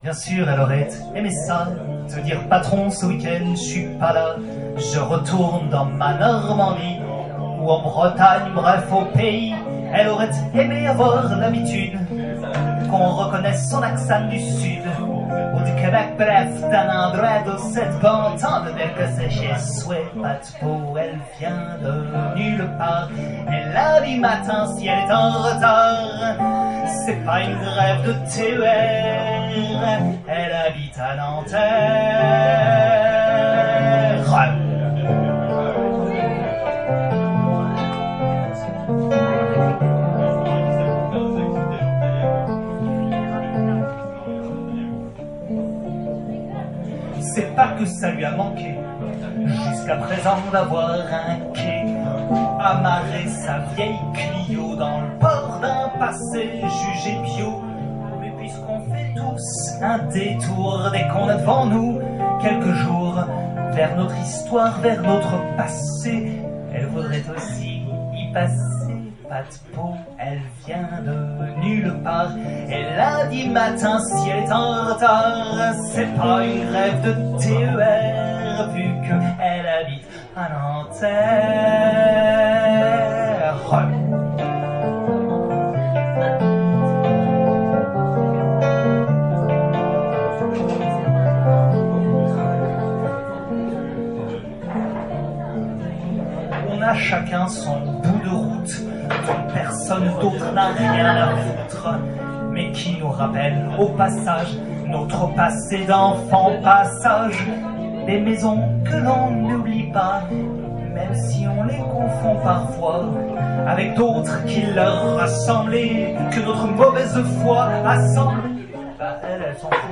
Chez Adel, 3 novembre 2016